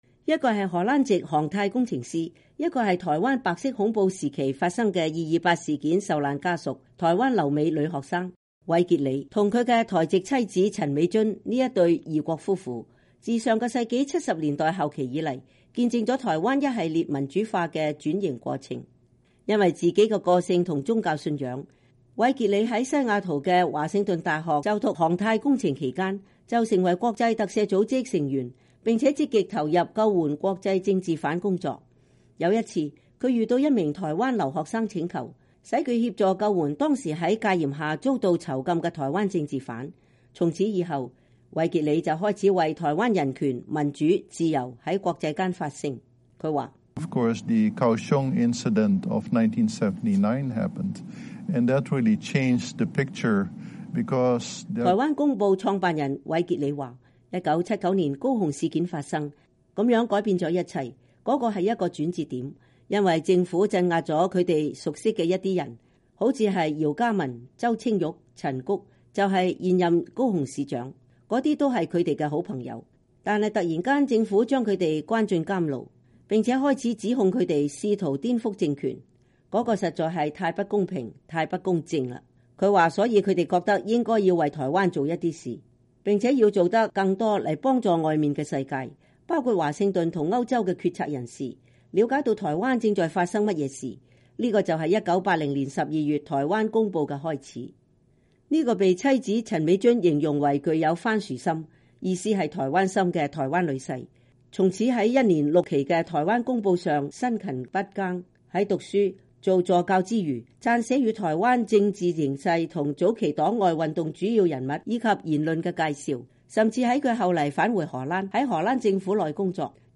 人物專訪：見證台灣民主化的《台灣公報》